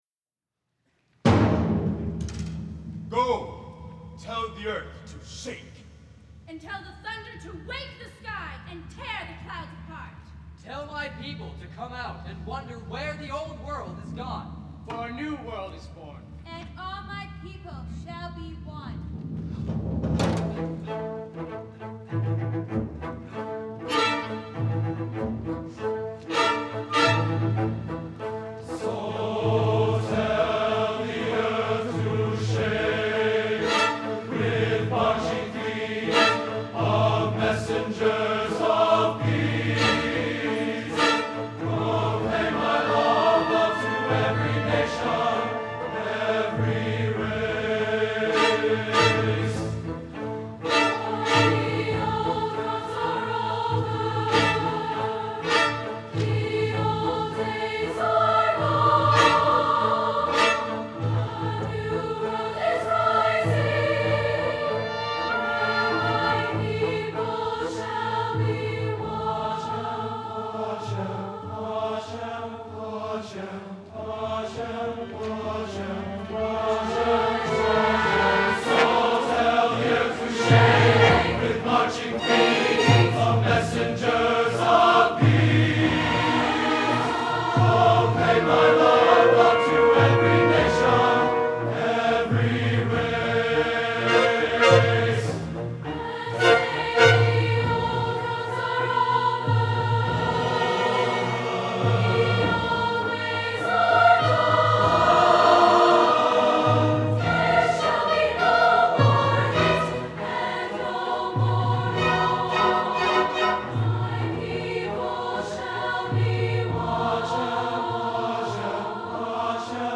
for SATB Chorus and Chamber Orchestra (2004-07)
As the lyrics are structured into verse pairings, "tell the earth to shake" and "the old wrongs are over," so the men's voices are paired with, and answered by, the women's voices. The alternation continues several times, with the tonal center shifting up a step with each section.